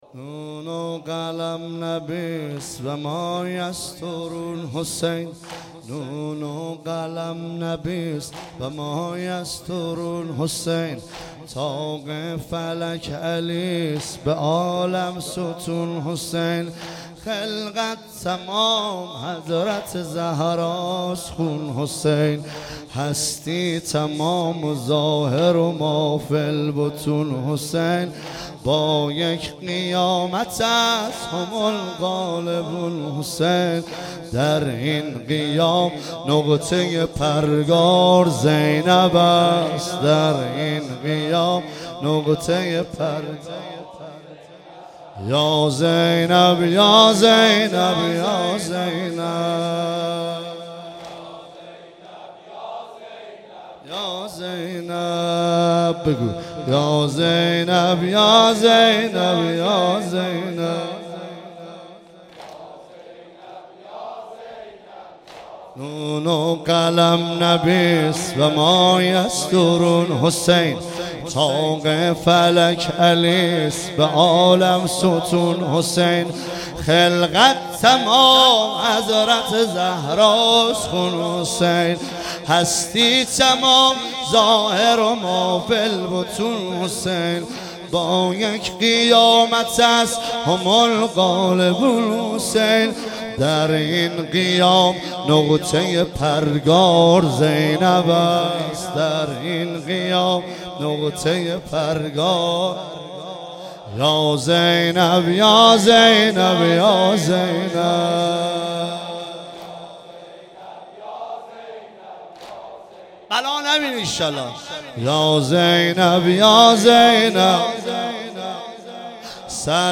مراسم یادبود ۱۶۷ شهید فرماندهی انتظامی خراسان
شب ۱۶ محرم الحرام ۱۳۹۶
واحد نون و قلم نبی است